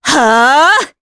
Veronica-Vox_Casting3_jp.wav